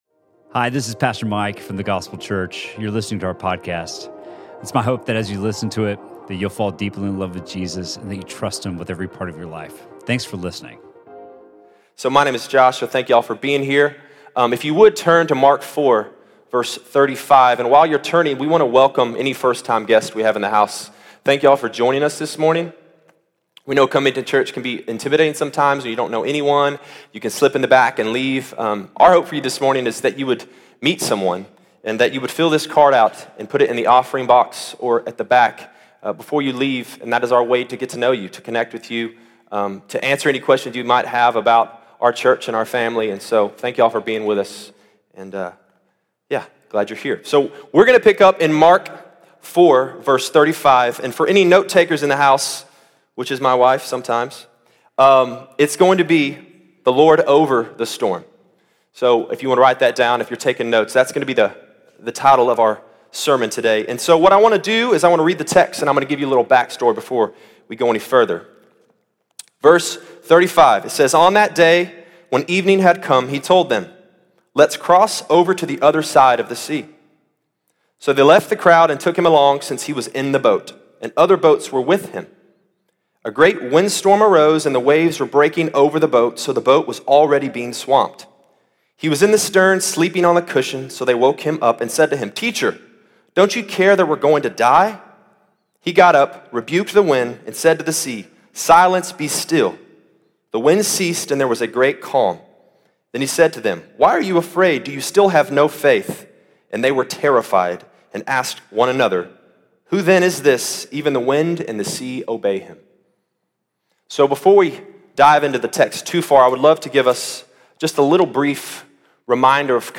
Sermon from The Gospel Church on May 26th, 2019.